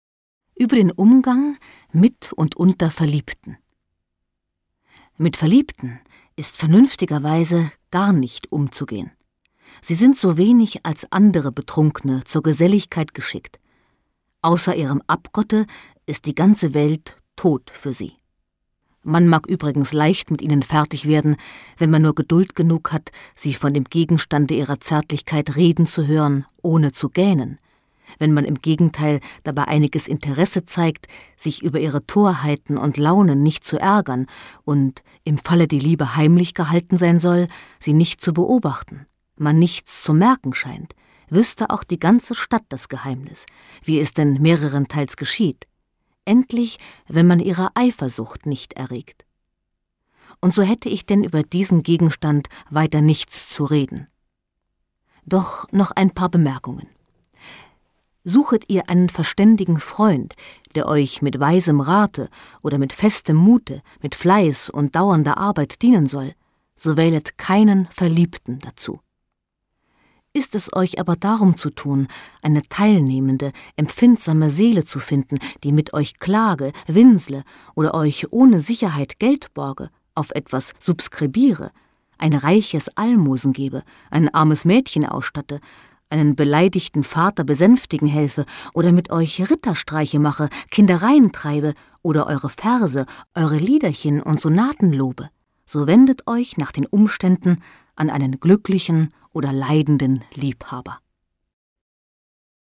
Adolph Freiherr von Knigge Sprecherin